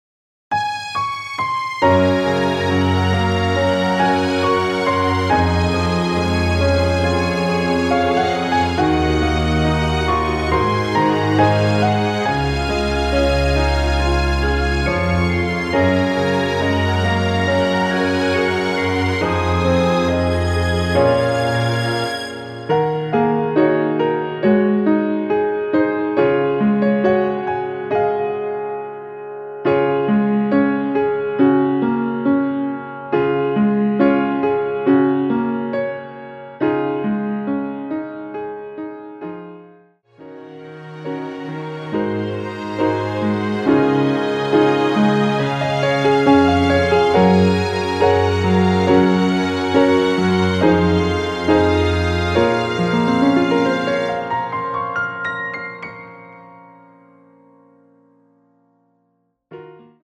초등학생이 부르기에 좋은 키 입니다.
Db
앞부분30초, 뒷부분30초씩 편집해서 올려 드리고 있습니다.
중간에 음이 끈어지고 다시 나오는 이유는